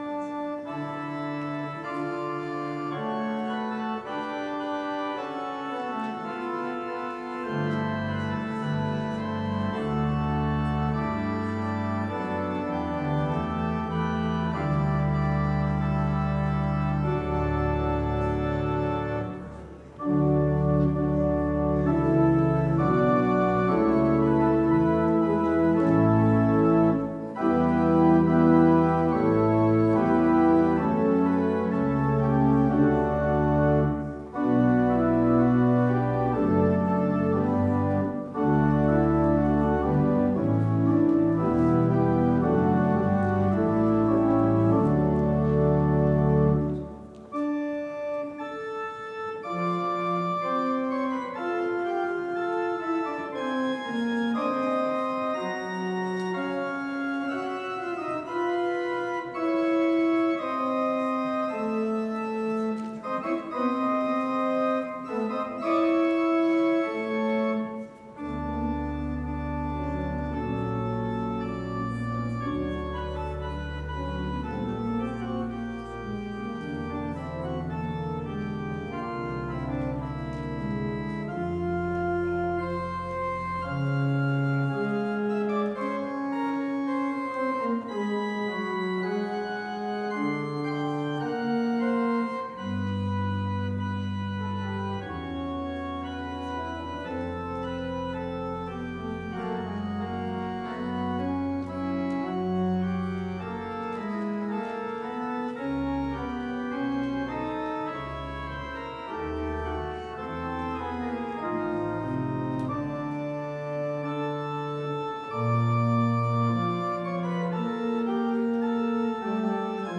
Worship Service Sunday, January 18, 2026